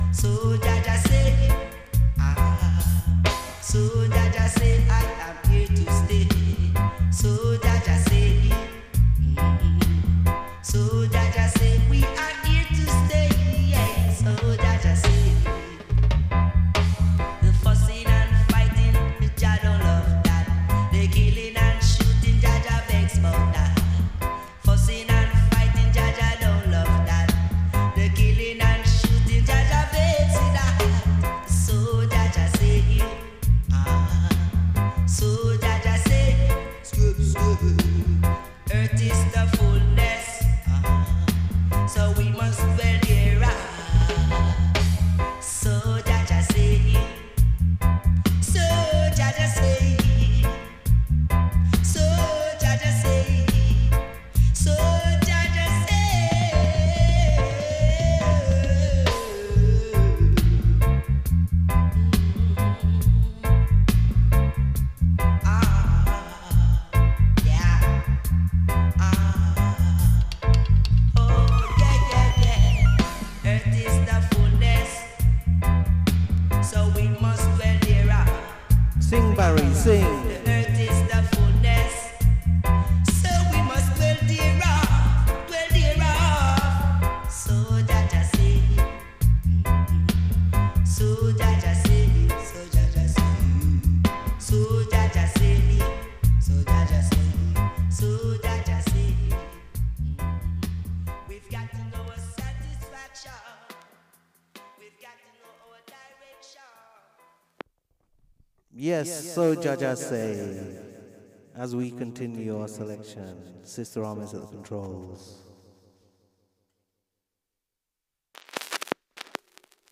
studio in Paris